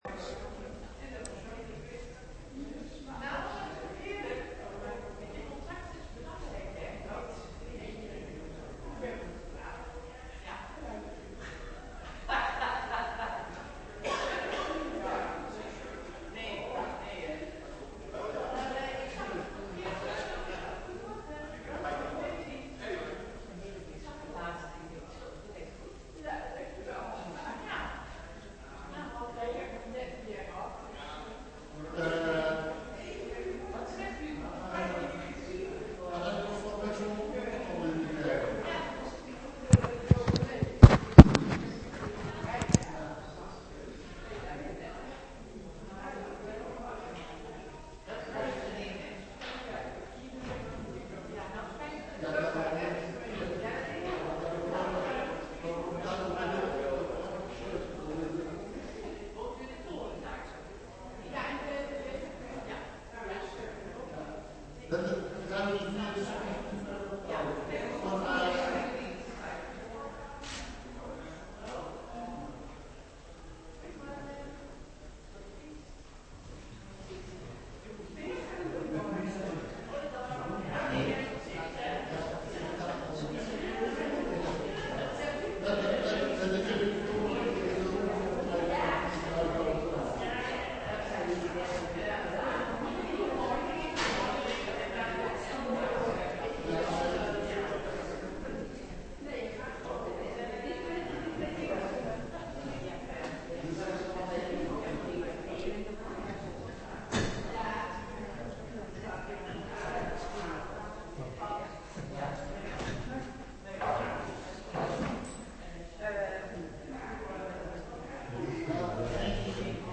Preek over Matteüs 3:16,17 op zondagmorgen 12 januari 2025 (doopdienst) - Pauluskerk Gouda